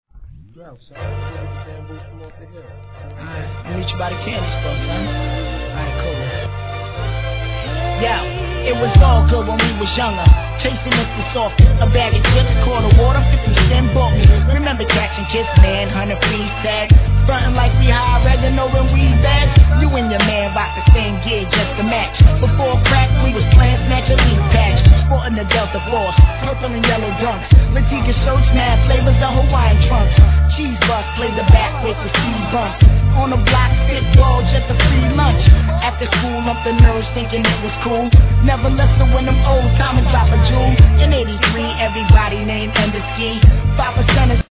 HIP HOP/R&B
こういった爽やか〜な作品もNICE!!